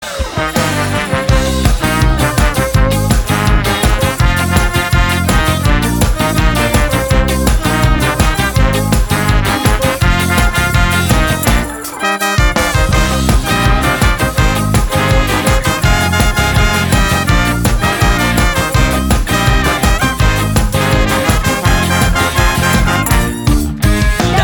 • Качество: 192, Stereo
веселые
без слов
скрипка
энергичные
русский рок
Веселая бодрящая мелодия на скрипке